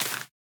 sapling_place1.ogg